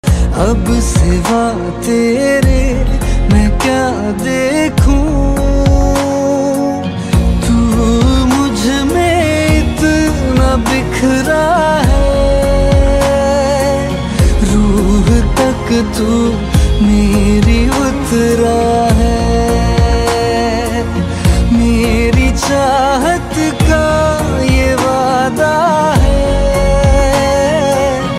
Ringtones Category: Bollywood